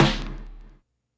snare.wav